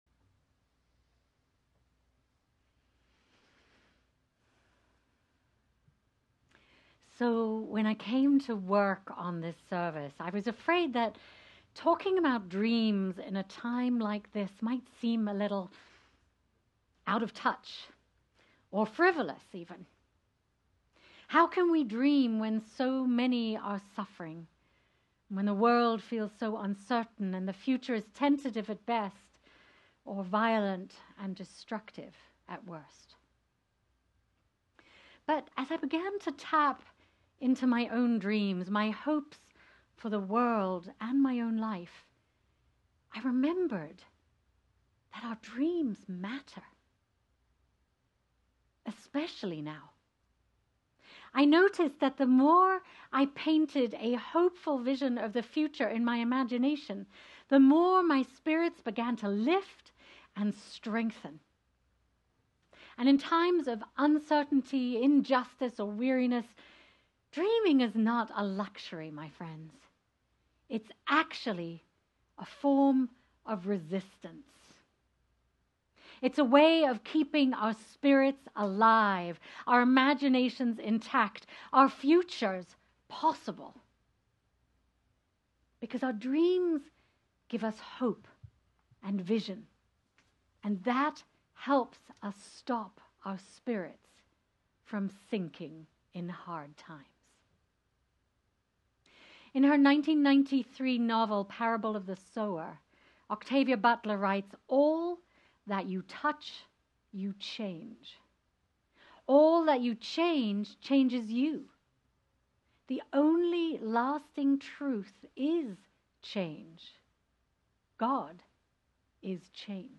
What dreams might take flight if nothing stood in your way? In this service of wonder and imagination, we’ll explore what becomes possible when we unshackle ourselves from limitation and dare to envision a life guided by our deepest longings.